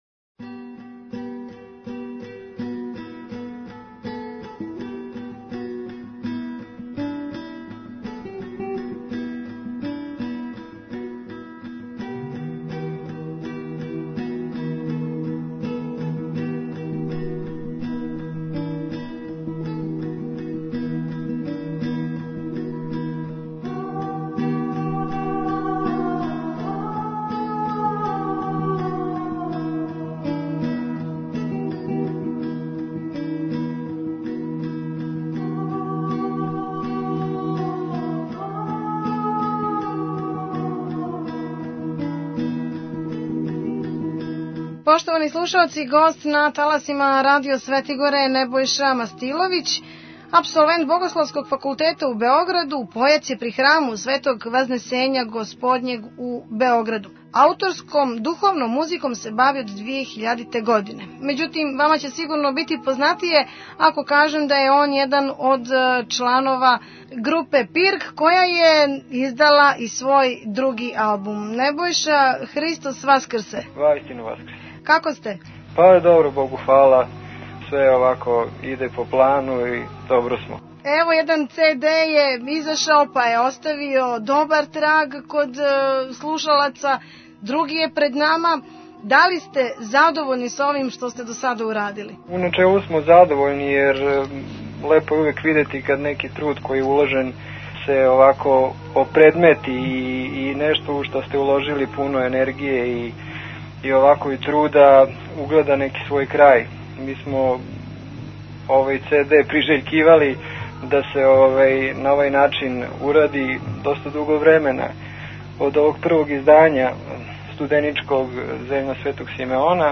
Актуелни разговори Наслов